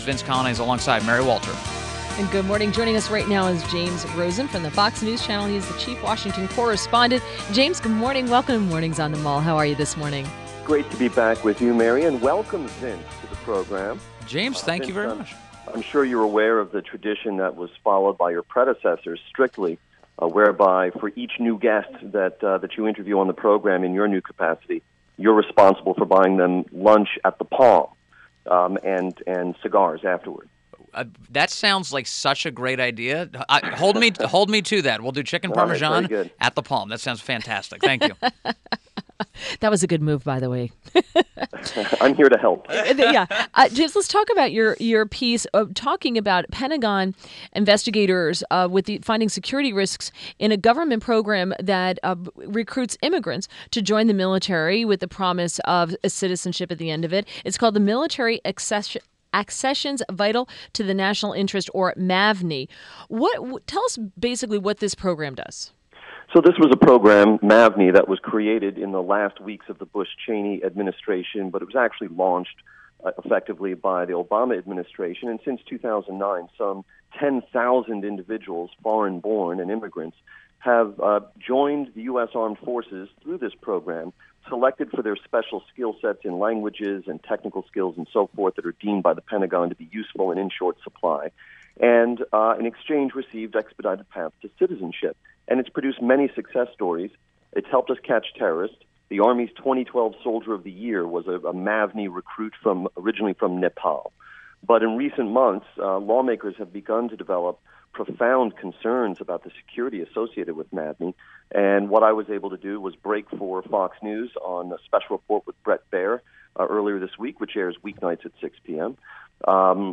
INTERVIEW -- JAMES ROSEN -- Chief Washington Correspondent, FOX NEWS